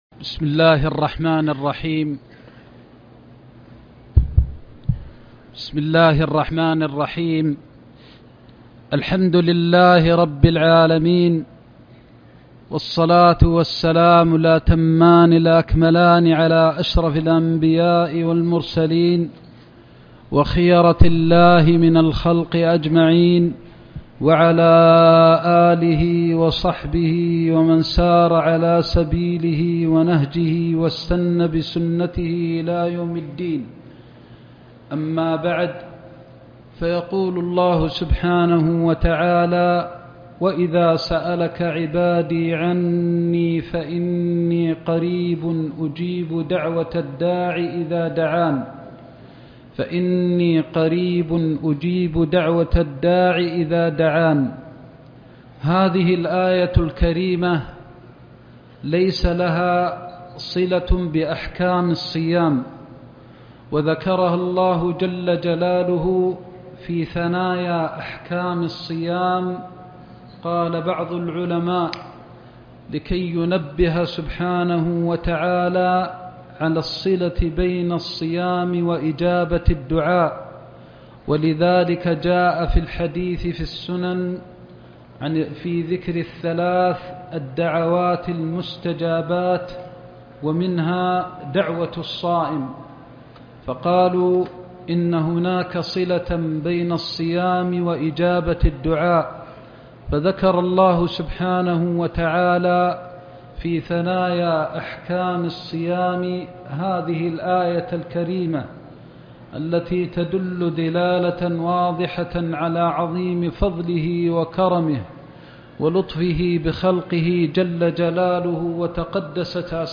درس الطائف في آيات الأحكام